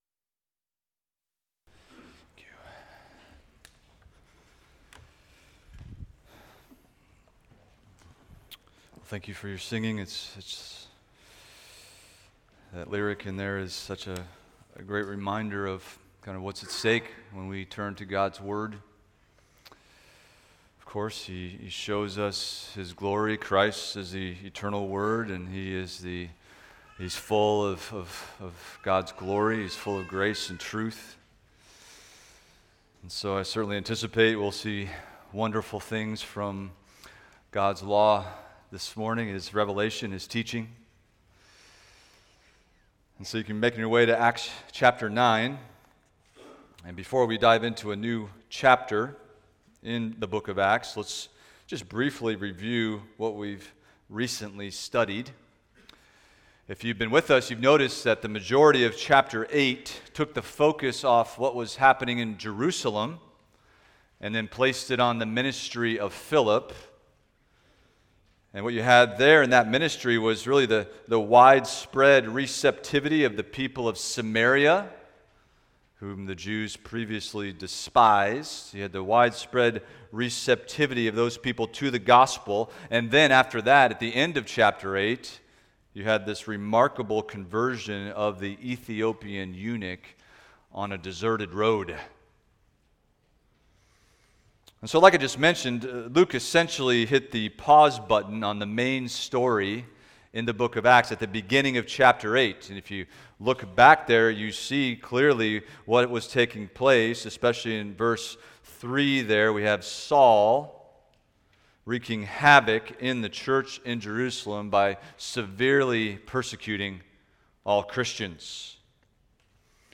Expository Preaching from First Peter – 1 Peter 1:6-9 Rejoicing Greatly in Hope